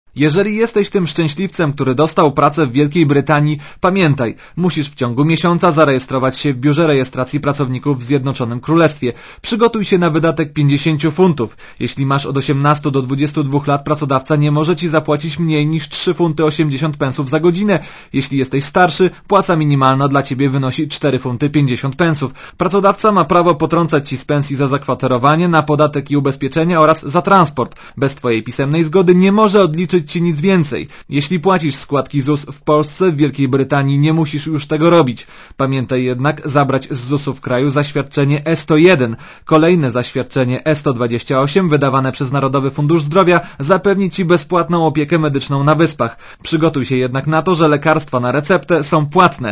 Relacja reportera Radia ZET Kampania informacyjna rządu brytyjskiego jest odpowiedzią na przyjazd do Anglii po pierwszym maja 2004 roku tysięcy Polaków.